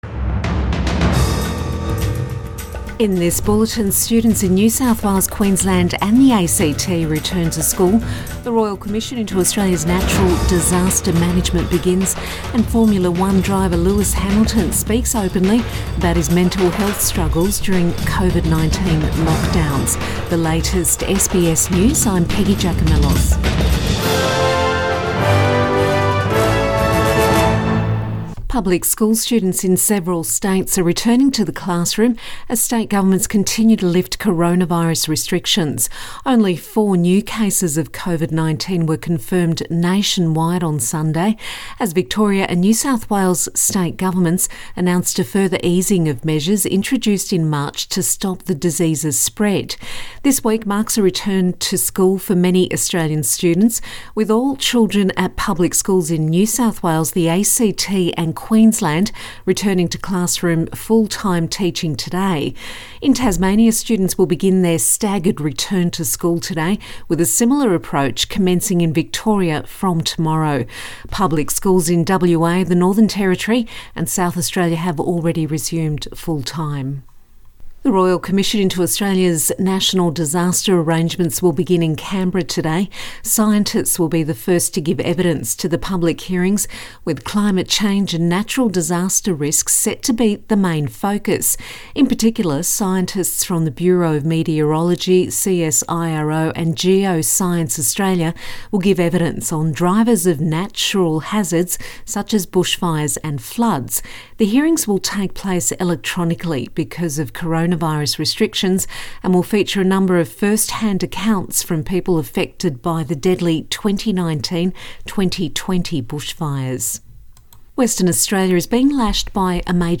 AM bulletin May 25 2020